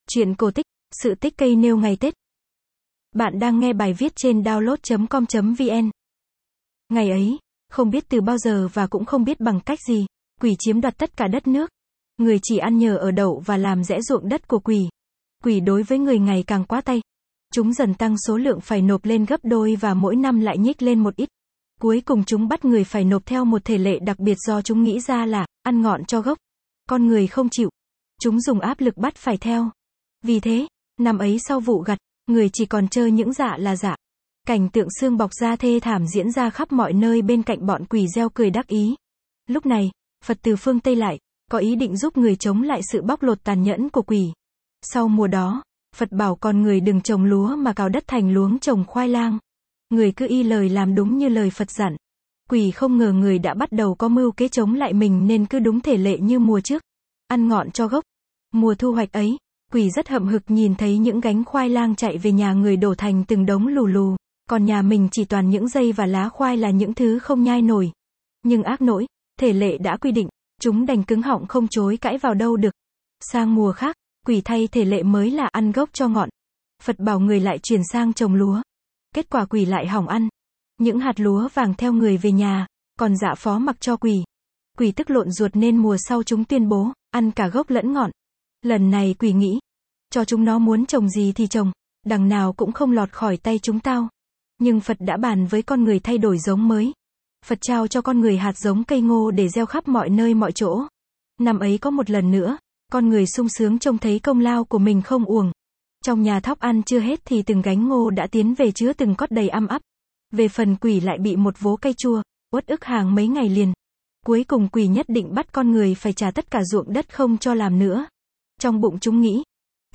Sách nói | Sự tích cây nêu ngày tết